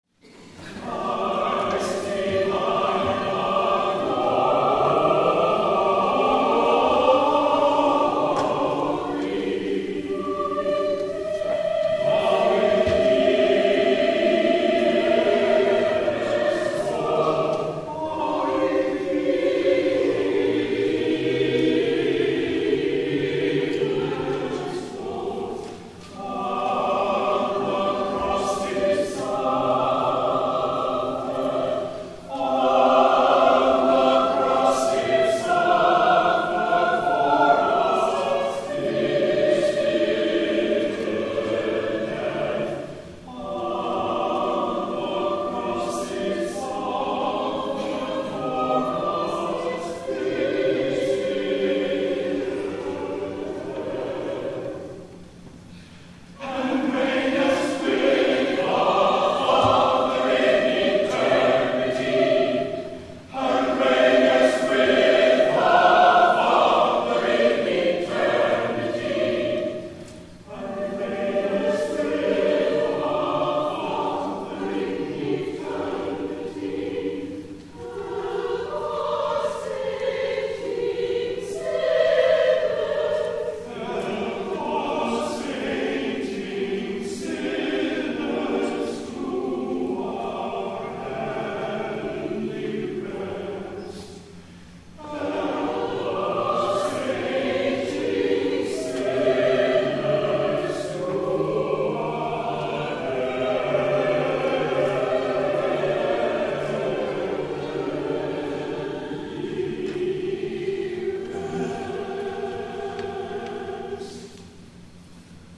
PALM SUNDAY
*THE CHORAL RESPONSE